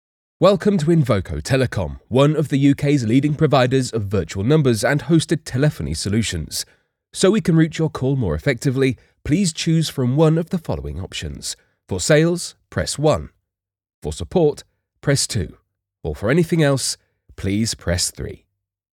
IVR Voiceover – Male M3
Male voiceover – M3 – Up to 120 words professionally recorded.
Professionally recorded voiceover for IVR’s, welcome greetings, system voicemails, etc., for up to 120 words.